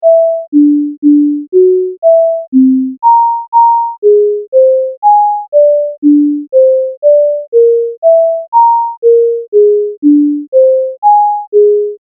This class shows a multi-note phrase can be rendered as an audio file.
This class creates a melodic phrase of 24 notes which is rendered with a sine wave instrument.